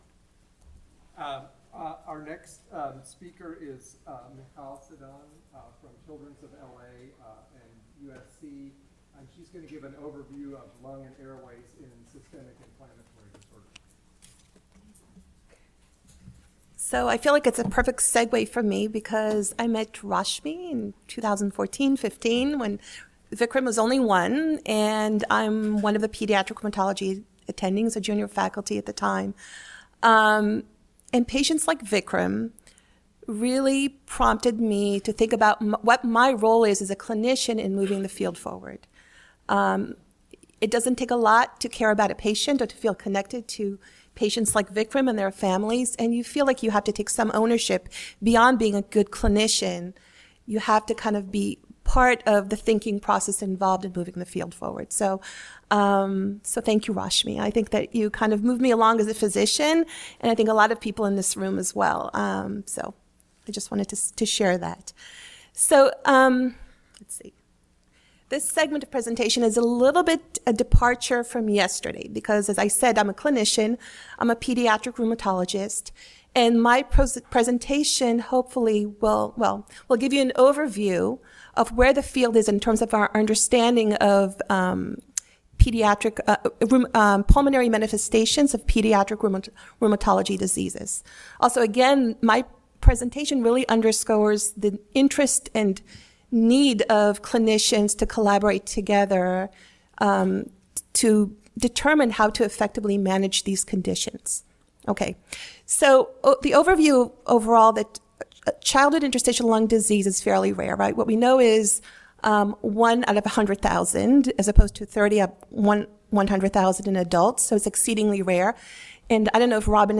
Rare Pediatric Respiratory Disease Conference Session 3